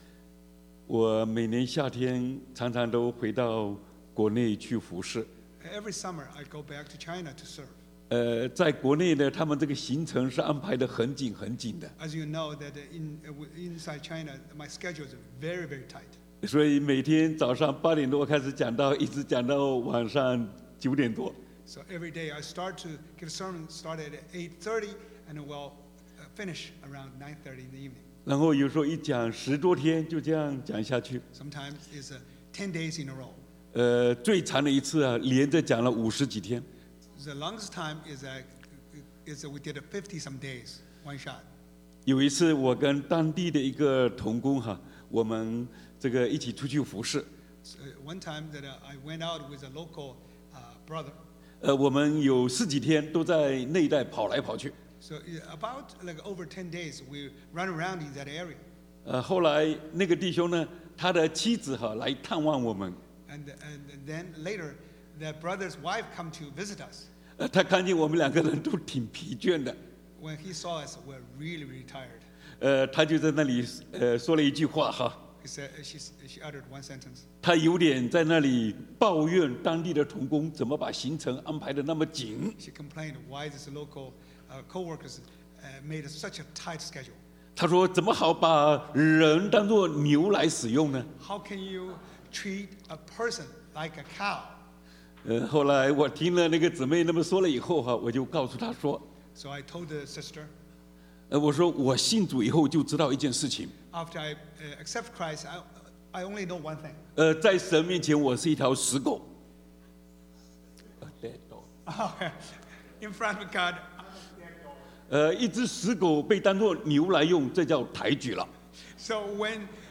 我們所領受的福音 The Gospel We Received （The 36Th CCCB Annual Mission Conference 1# Message）